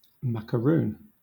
wymowa:
bryt. IPA/ˌmæk.əɹˈuːn/
amer. IPA/ˌmæk.əˈɹun/